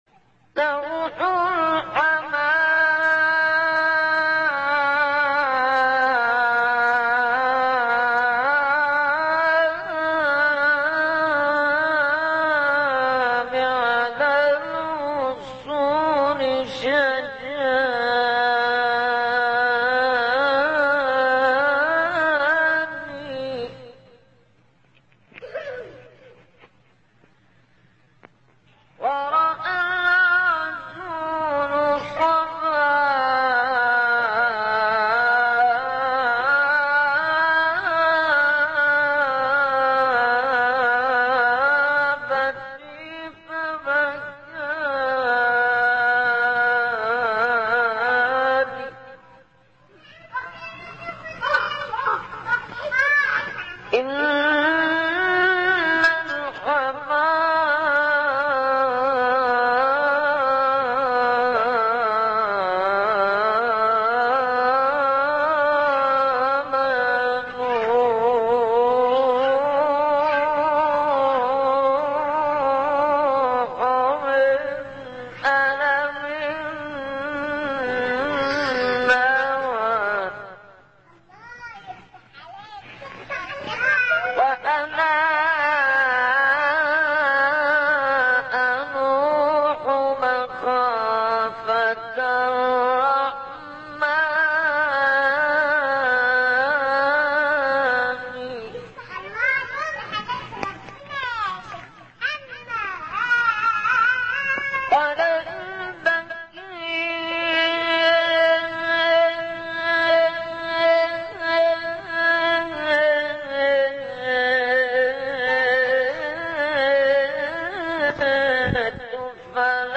ابتهال کمتر شنیده شده از احمد شبیب